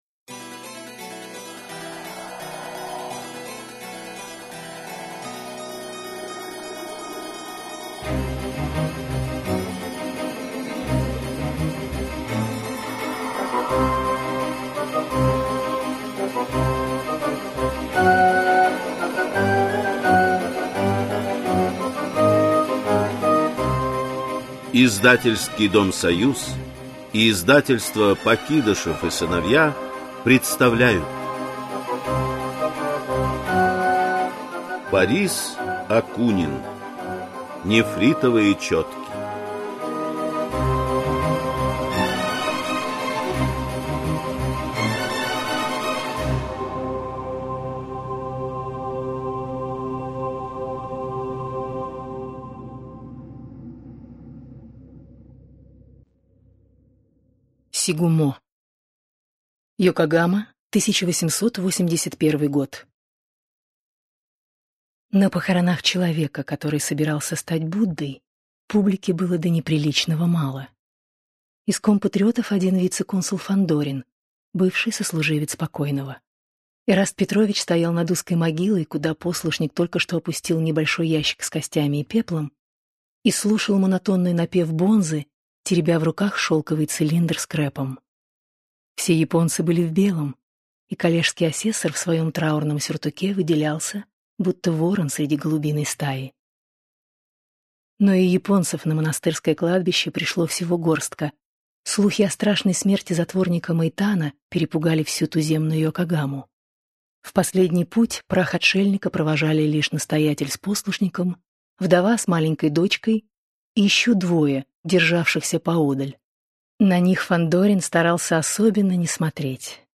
Аудиокнига Сигумо | Библиотека аудиокниг
Прослушать и бесплатно скачать фрагмент аудиокниги